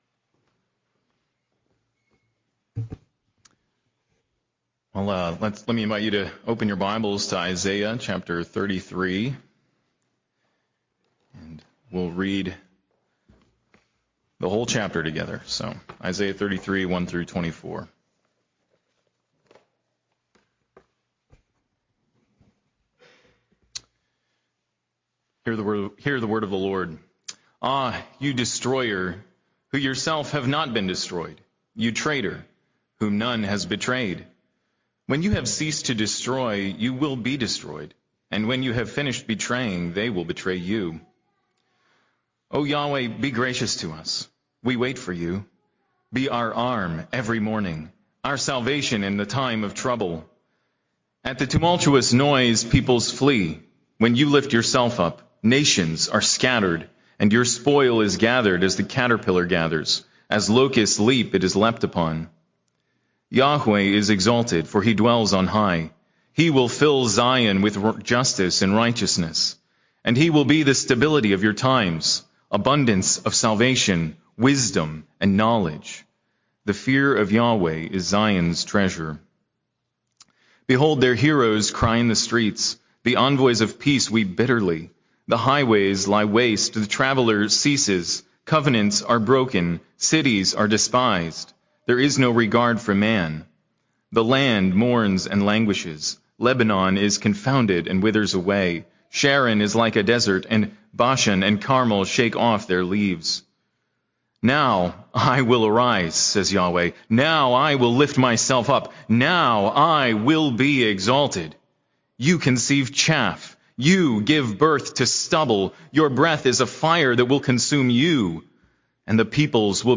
New Hope Grounded in Future Sight: Sermon on Isaiah 33 - New Hope Presbyterian Church